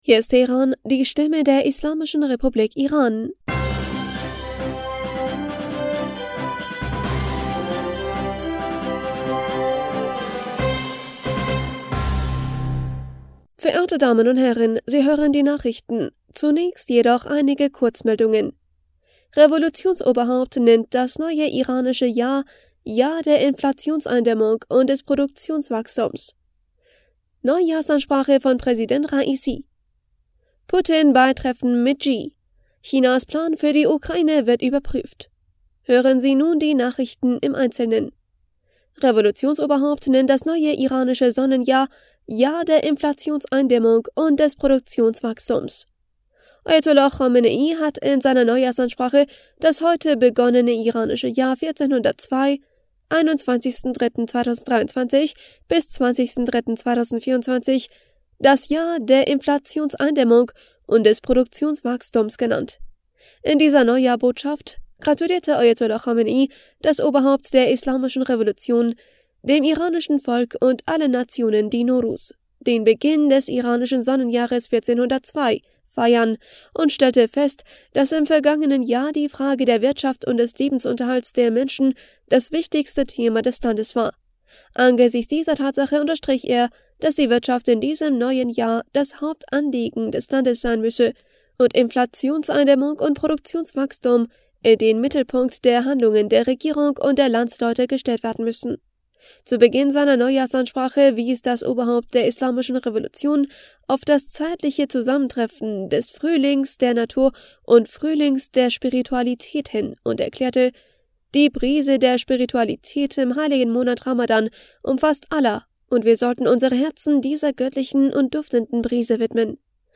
Nachrichten vom 21. März 2023